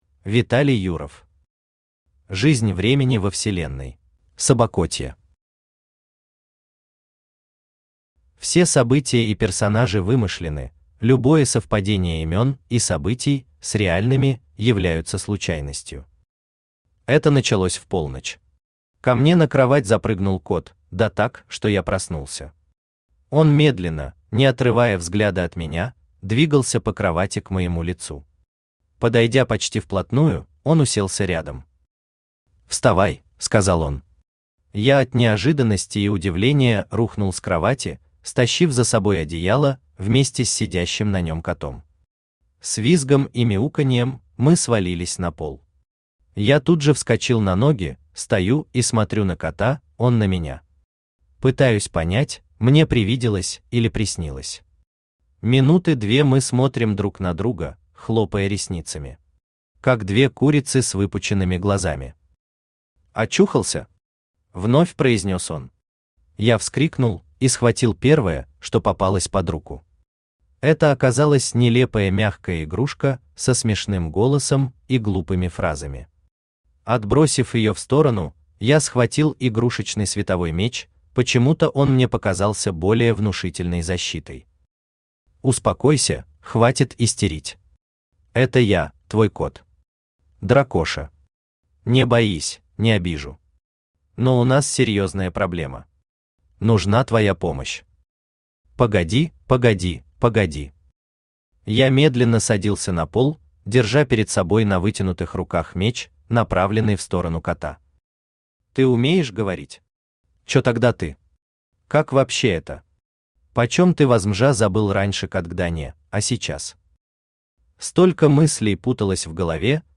Аудиокнига Жизнь времени во вселенной | Библиотека аудиокниг
Aудиокнига Жизнь времени во вселенной Автор Виталий Юров Читает аудиокнигу Авточтец ЛитРес.